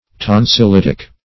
Tonsilitic \Ton`sil*it"ic\